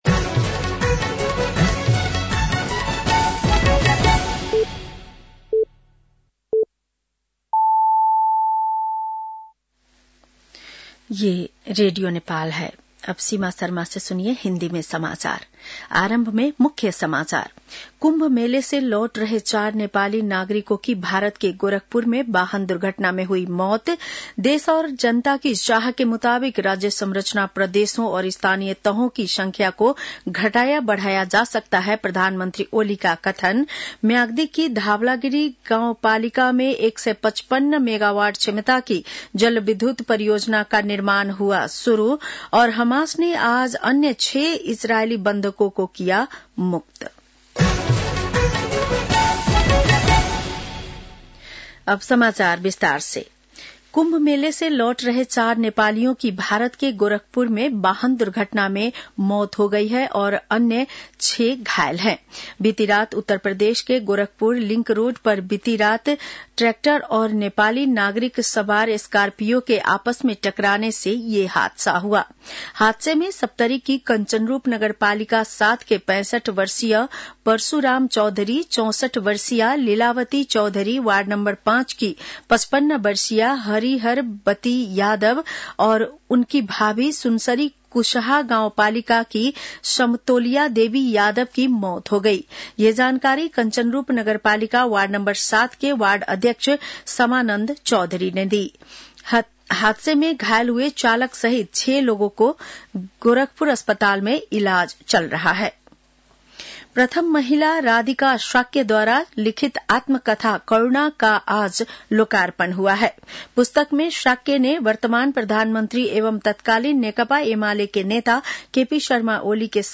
बेलुकी १० बजेको हिन्दी समाचार : ११ फागुन , २०८१